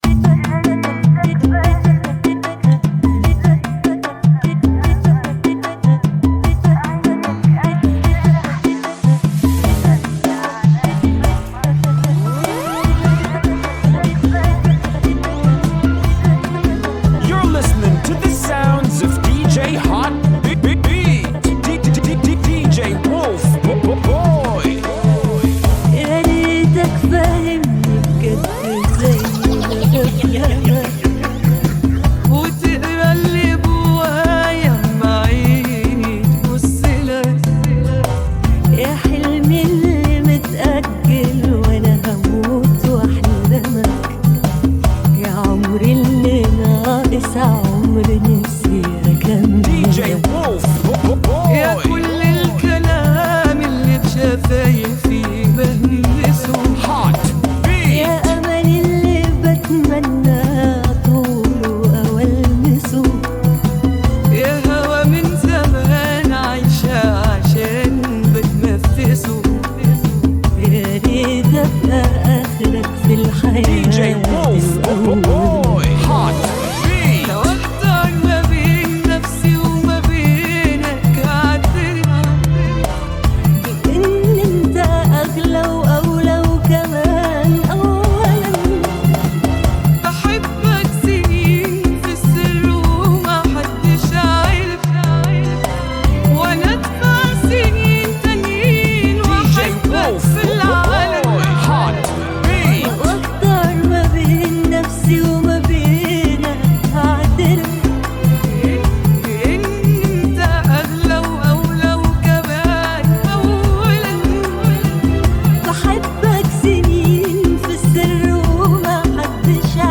ريمكس ) [ AI Remix